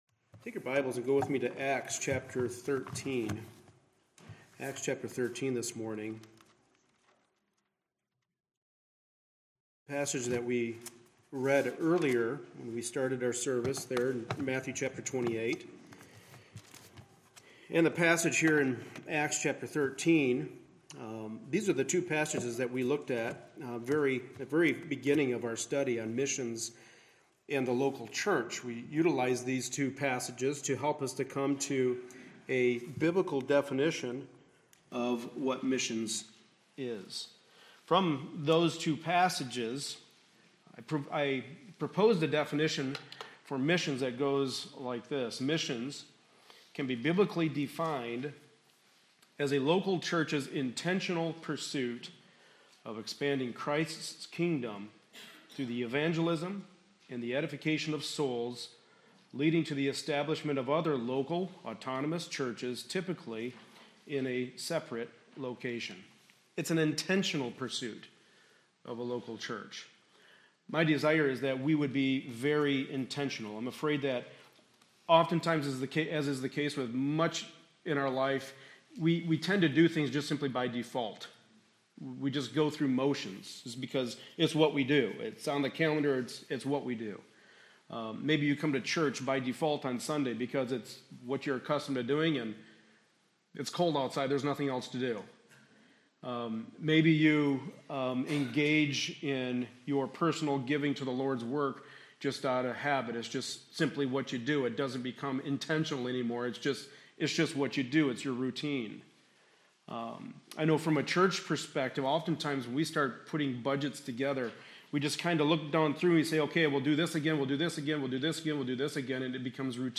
Passage: Acts 13-14 Service Type: Sunday Morning Service Related Topics